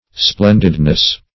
Splendidness \Splen"did*ness\, n. The quality of being splendid.